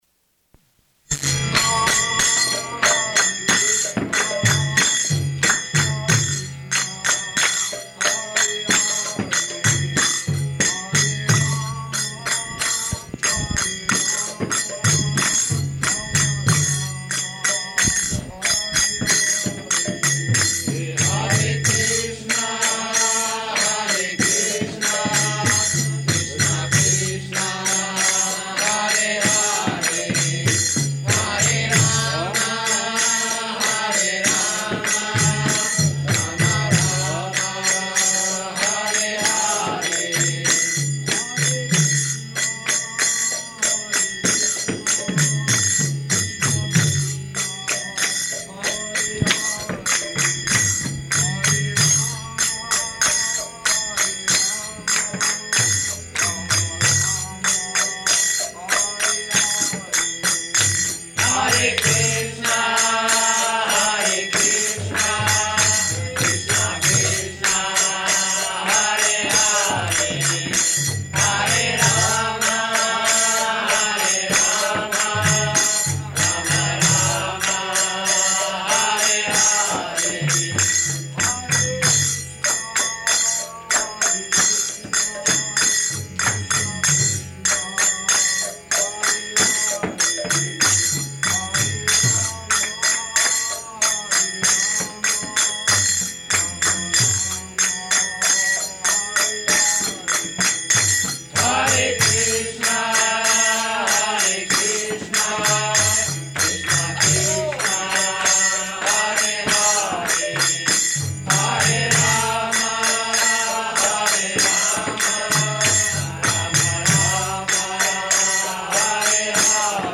Bhagavad-gītā 7.1 --:-- --:-- Type: Bhagavad-gita Dated: December 2nd 1968 Location: Los Angeles Audio file: 681202BG-LOS_ANGELES.mp3 Prabhupāda: [ kīrtana ] [ prema-dhvanī ] Thank you very much.